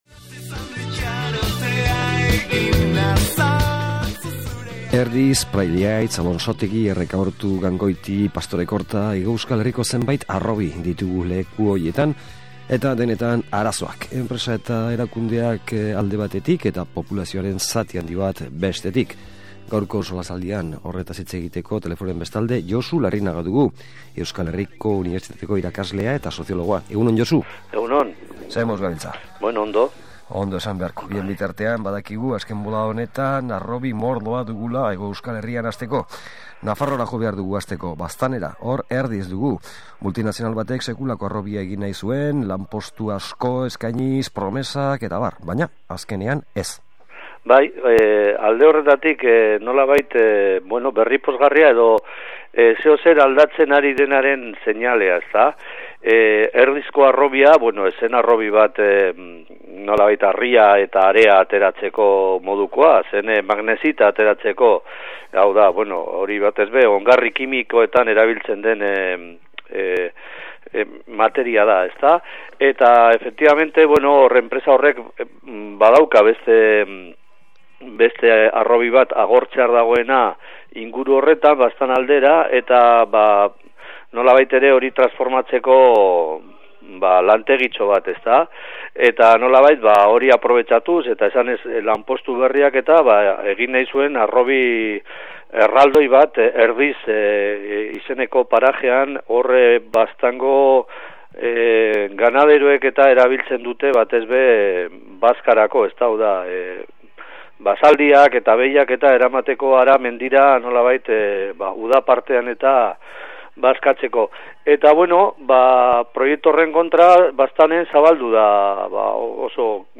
SOLASALDIA: Harrobiak aztergai